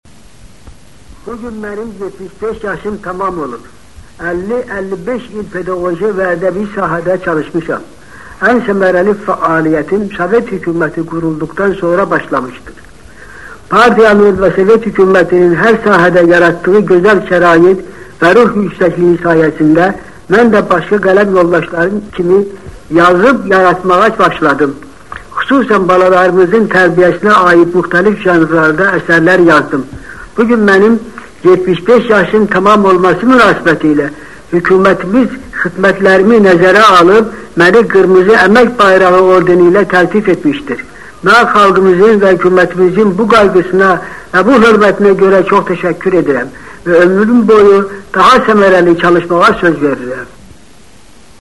VOICE RECORDING
Addressing the audience during an award ceremony of the Order of Red Banner of Labour on the occasion of 75 year anniversary of Abdulla Shaig, 1956.